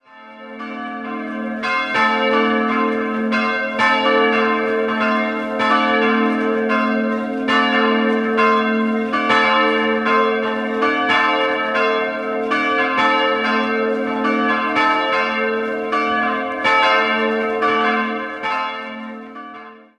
3-stimmiges TeDeum-Geläute: a'-c''-d''
Die beiden großen Glocken (Johannes und Maria geweiht) wurden 1954 von Georg Hofweber in Regensburg gegossen, die kleine Herz-Jesu-Glocke stammt aus dem Jahr 1949 aus der Gießerei Hamm.
Alle Glocken werden noch per Hand geläutet und sind weit ins Umland zu hören.
Freudenberg_Johannisbergkirche.mp3